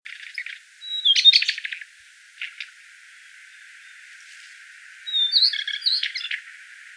s1-12012mar25石山小翼鶇.WAV
物種名稱 小翼鶇 Brachypteryx montana goodfellowi
錄音地點 南投縣 信義鄉 石山
錄音環境 灌木叢
行為描述 鳥叫
錄音: 廠牌 Denon Portable IC Recorder 型號 DN-F20R 收音: 廠牌 Sennheiser 型號 ME 67